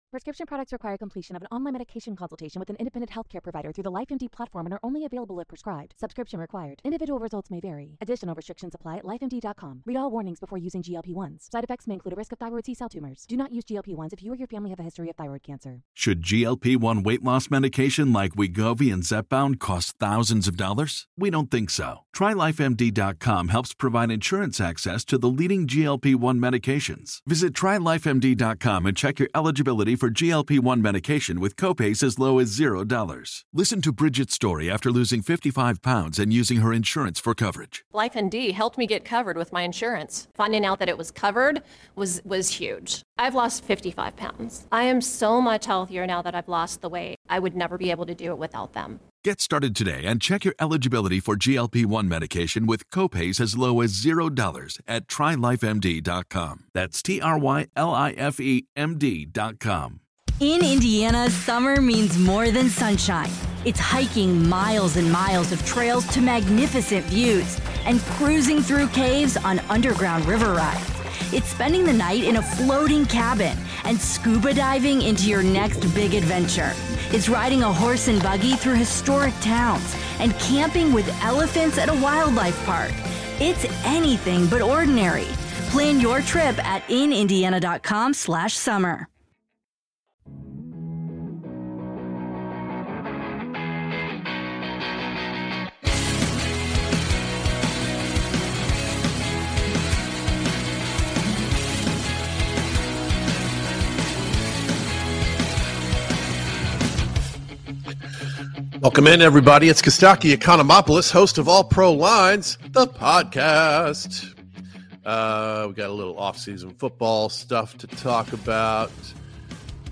Reactions, debate, jokes, trash talk and comedian guests.